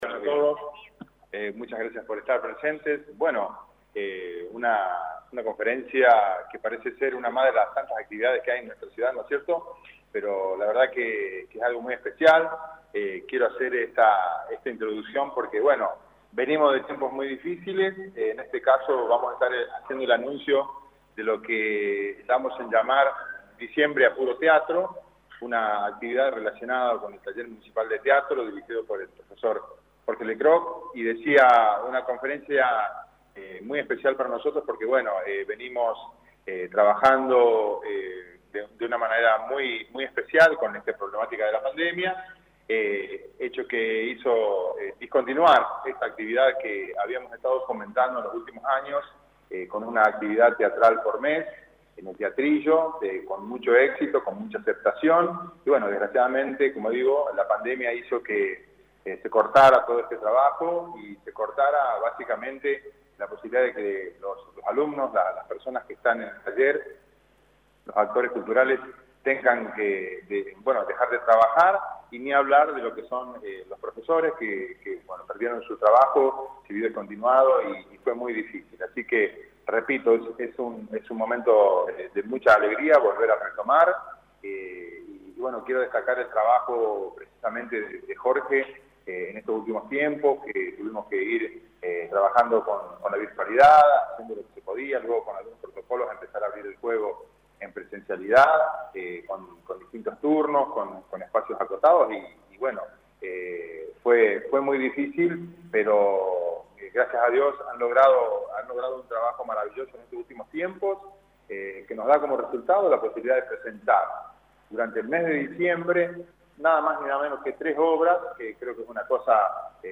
El anuncio fue mediante conferencia de prensa encabezada por el secretario de Cultura Germán Argañaráz